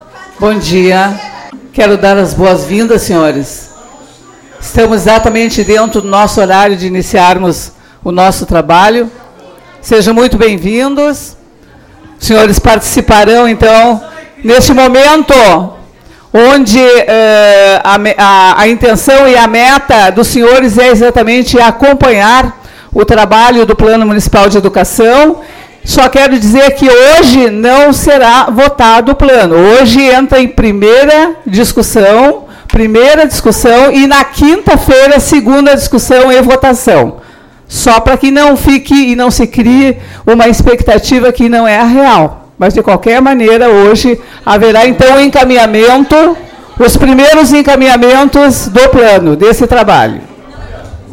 Sessão 08/12/2015